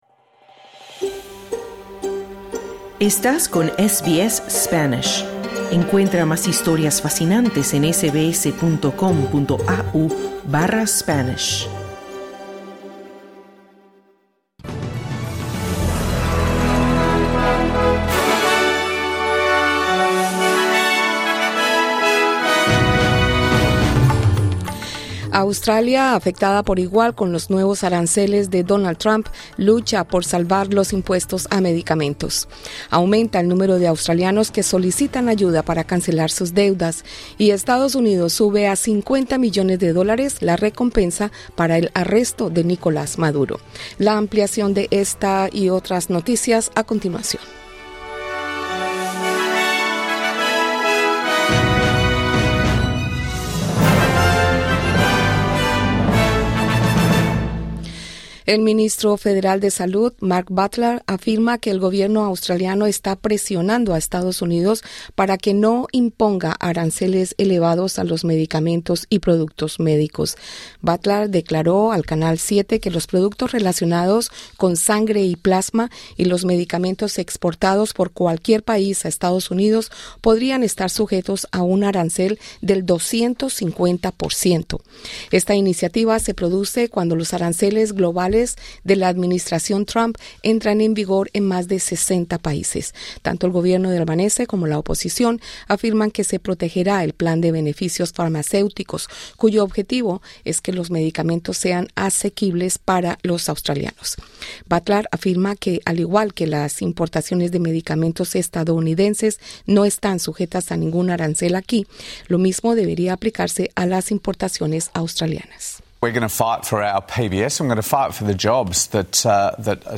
Para escuchar el boletín de noticias de este viernes, pulsa el botón de reproducción de audio que aparece al inicio de esta página.